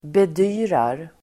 Ladda ner uttalet
Uttal: [bed'y:rar]